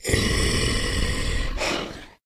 sounds / monsters / psysucker / idle_5.ogg